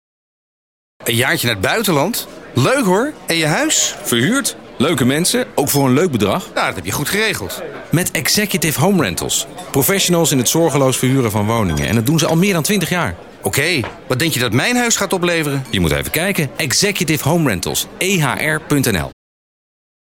If you are able to understand Dutch, you can listen to our radio commercial on BNR Nieuwsradio below.